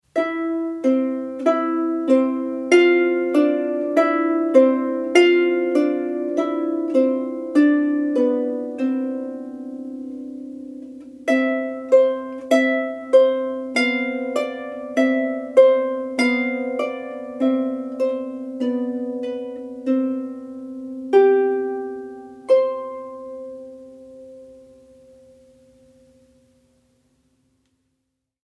fiaba narrata e musicata
Arpa 1